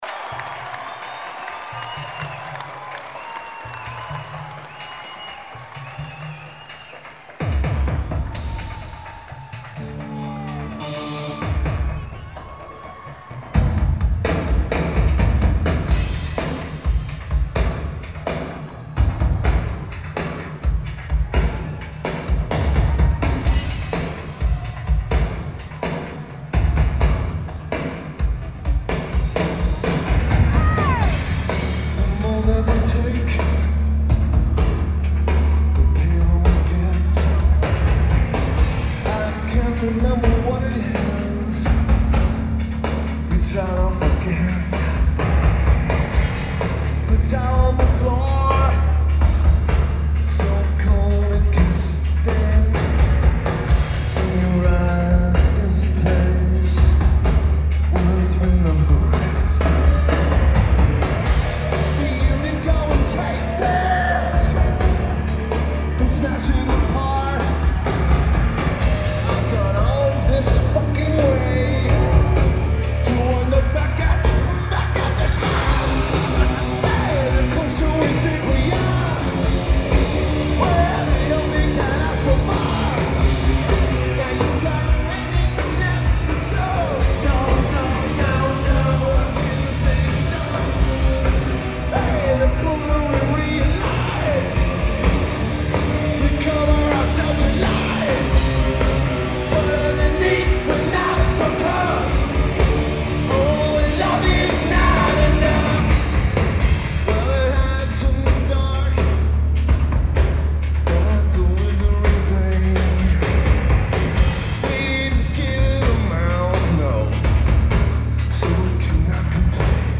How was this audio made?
Brixton Academy Lineage: Audio - AUD (Core Sound HEB + BB + Sony TCD-D100) It sounds fantastic.